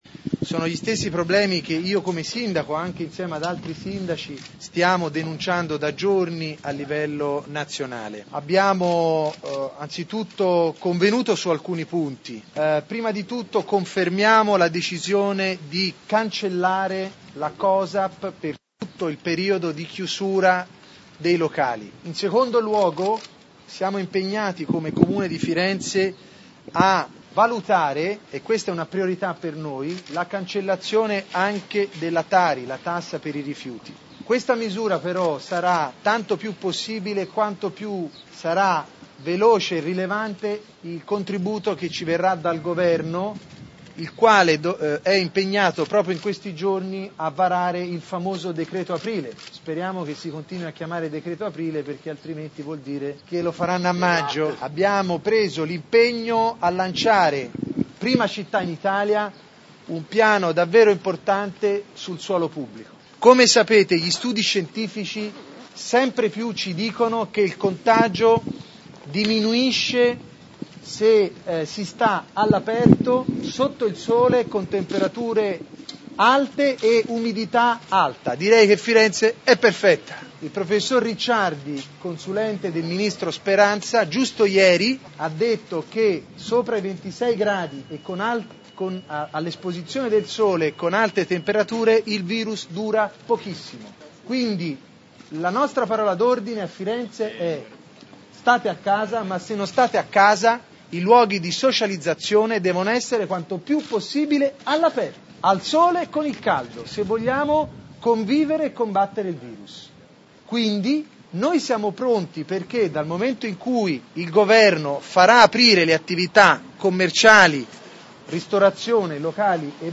Ascolta le dichiarazioni rilasciate dal Sindaco Nardella